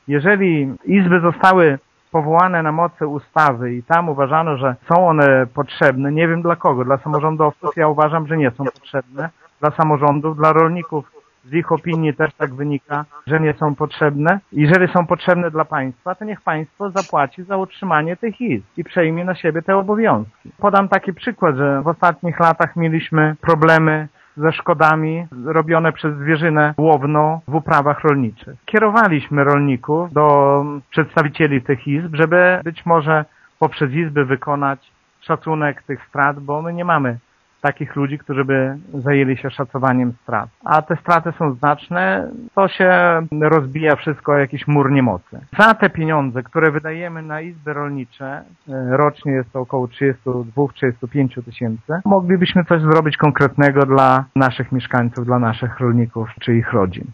Oprócz apelu do samorządowców wójt Szopiński zwraca się również do parlamentarzystów o takie zmiany przepisów, by to państwo przejęło finansowanie izb rolniczych: